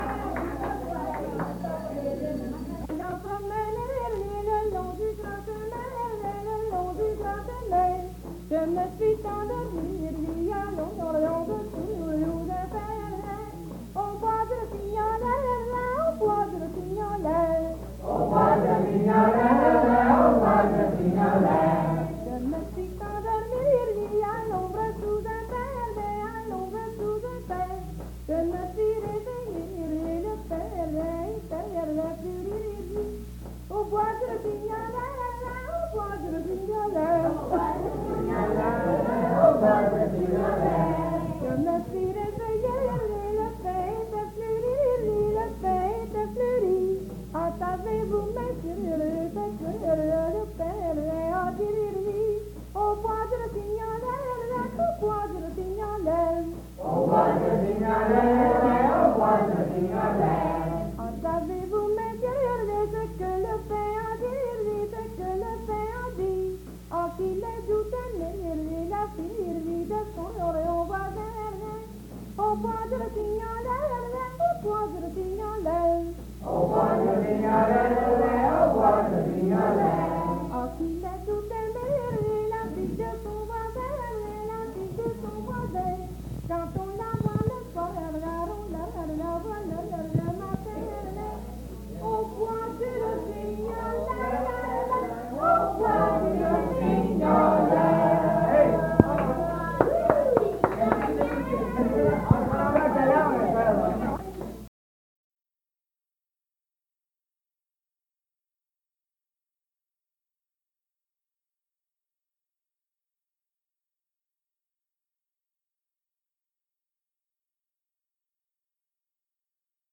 Folk Songs, French--New England
Song